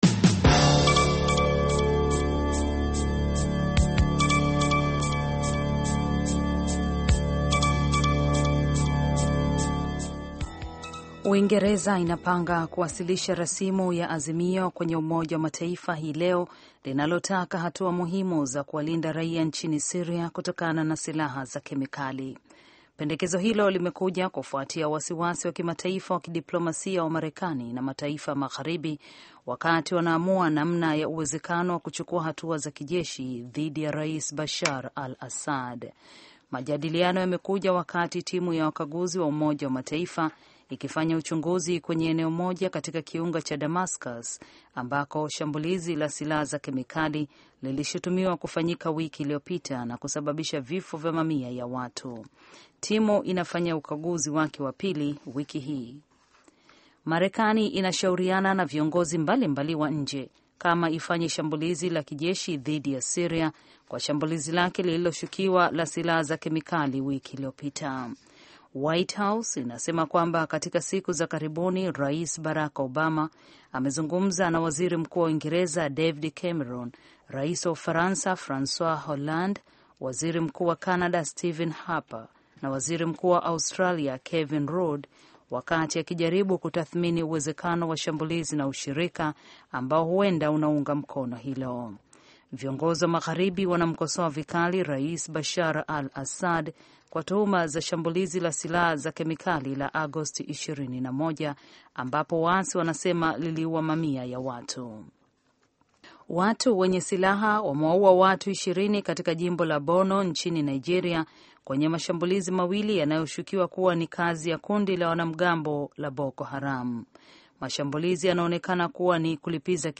Taarifa ya Habari VOA Swahili - 6:05